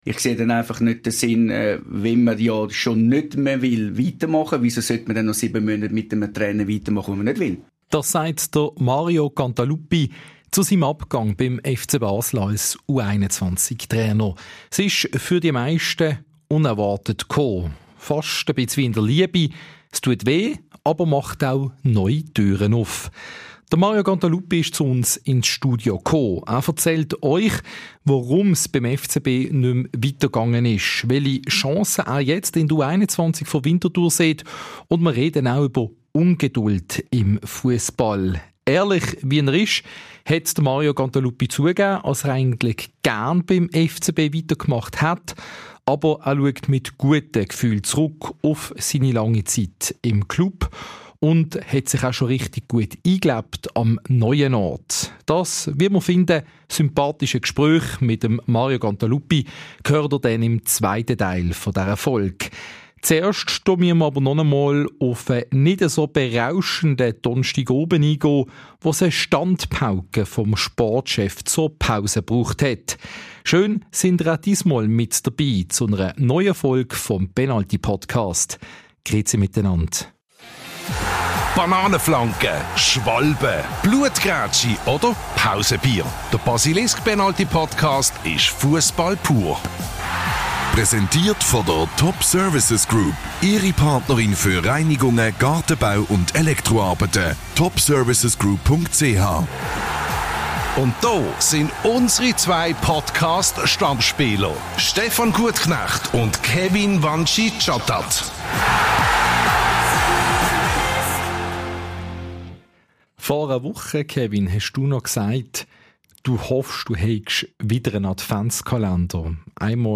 Unser Podcast-Duo ordnet die Leistung ein und kritisiert den Auftritt einiger Spieler. Im zweiten Teil der Folge hört ihr das Gespräch mit Mario Cantaluppi.